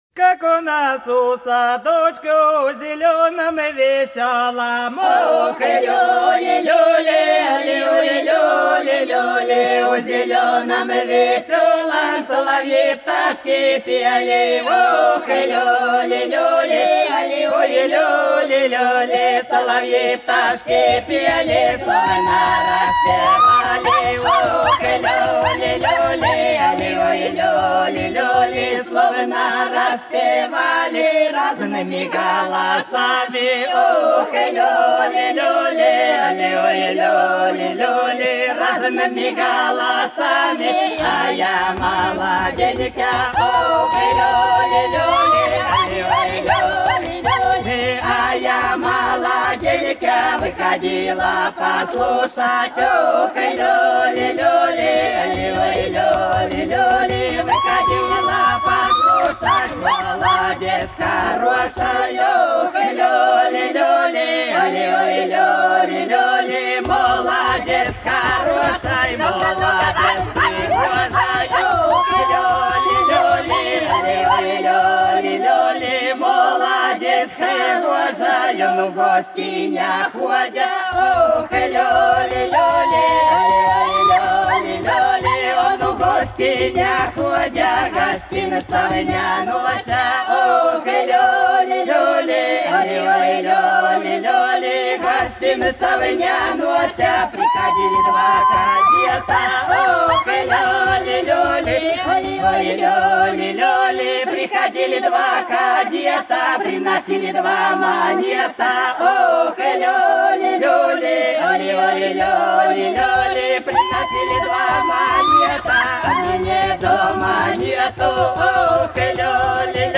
Хороша наша деревня Как у нас у садочку - плясовая (с. Афанасьевка)